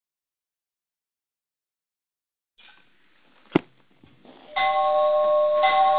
Звонок в дверь